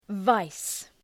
{‘vi:zeı}